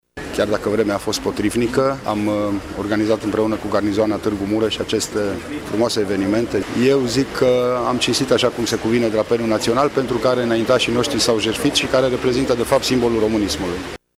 Prefectul județului Mureș, Lucian Goga: